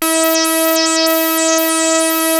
Index of /90_sSampleCDs/Hollywood Edge - Giorgio Moroder Rare Synthesizer Collection/Partition A/ARP 2600 7